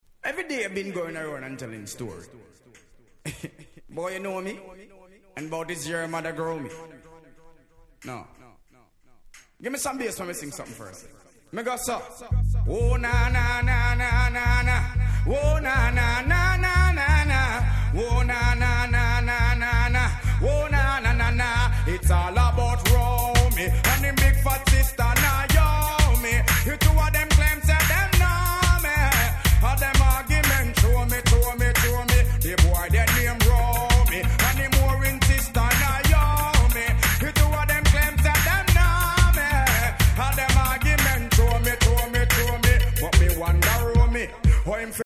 問答無用のDancehall Classic !!